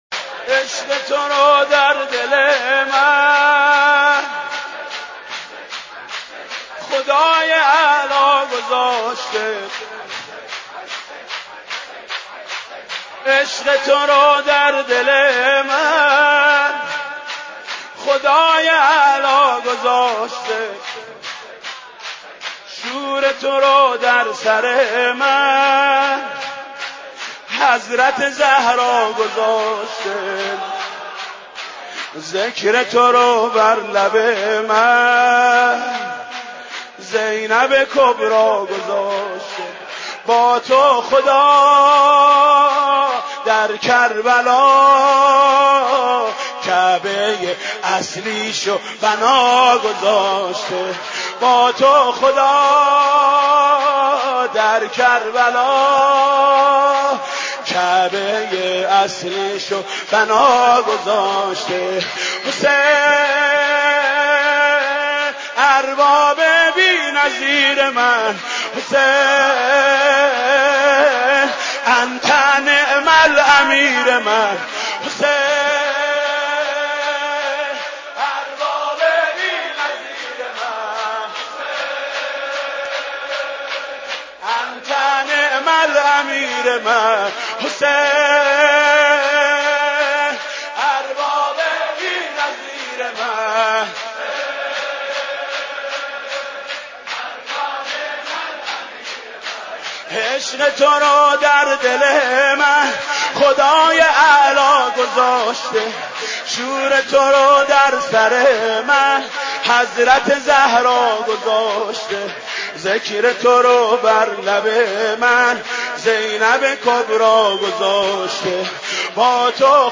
ویژه ایام محرم و سوگواری